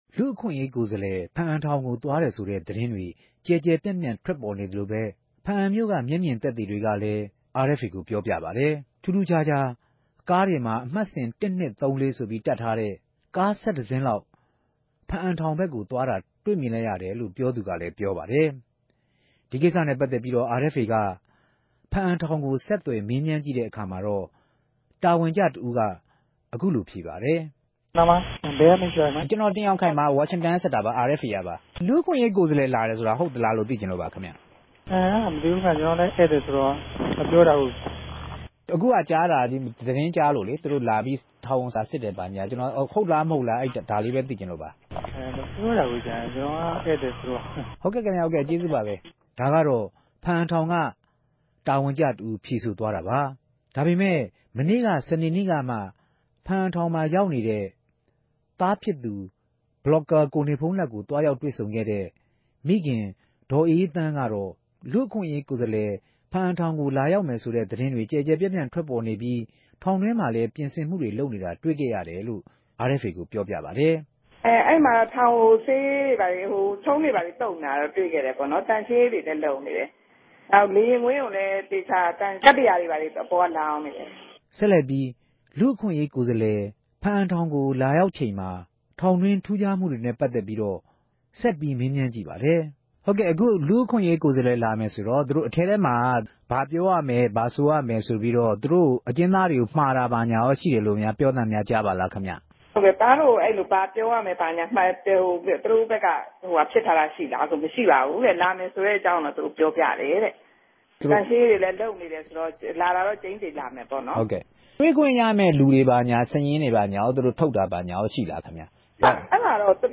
ဖားအံထောင်ကို သြားရောက်ခဲ့တယ်ဆိုတာနဲႛ ပတ်သက်္ဘပီး ဖားအ္ဘံမိြႛခံတဦးက ခုလိုေူပာူပပၝတယ်။
ဆက်သြယ်မေးူမန်းခဵက်။